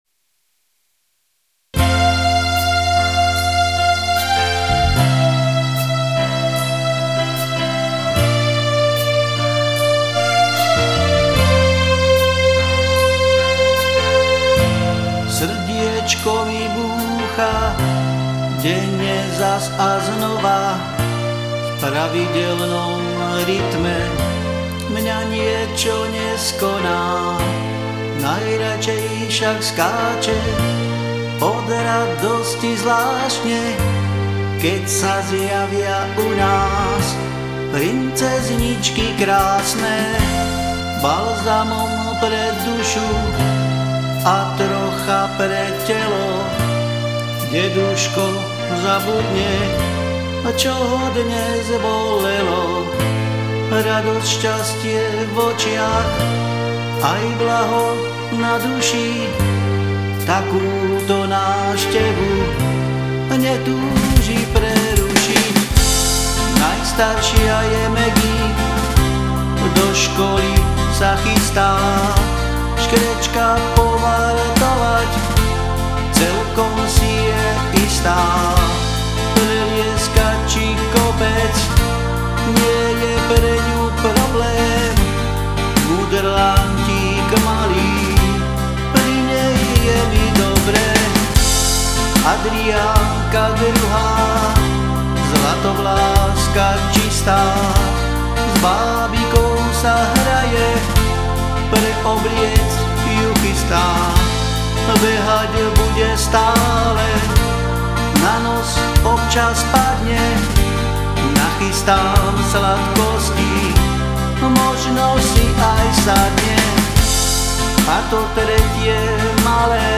Som amatérsky muzikant, skladám piesne väčšinou v "záhoráčtine" a tu ich budem prezentovať.
Piesne POP